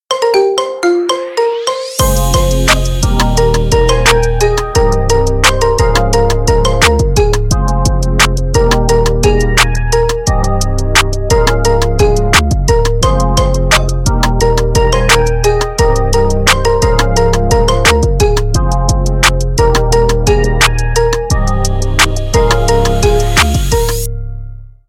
маримба